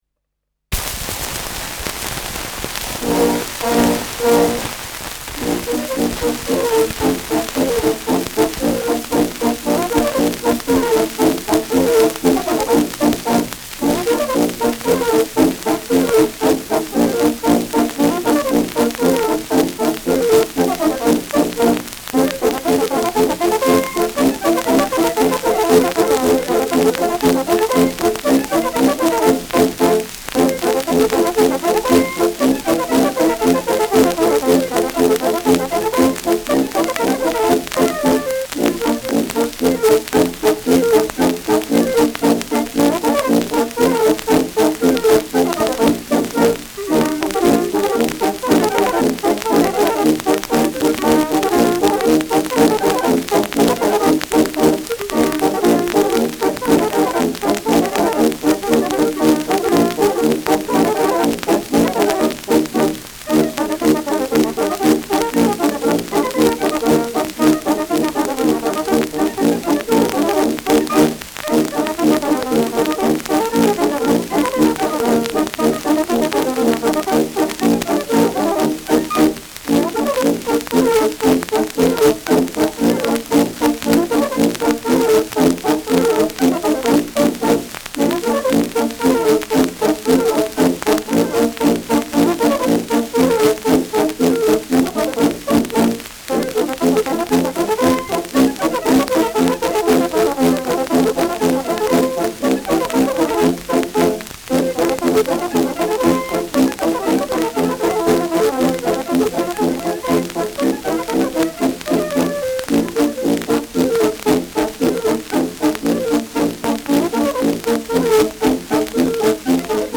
Schellackplatte
präsentes Rauschen : präsentes Knistern : abgespielt : leichtes „Schnarren“
Militärmusik des k.b. 14. Infanterie-Regiments, Nürnberg (Interpretation)
Dachauer Bauernkapelle (Interpretation)
Im Vergleich zeigen sich Unterschiede, wie sie das freie Spiel ohne Noten oder auch nur ein anderer Tubist hervorbringen.
[München?] (Aufnahmeort)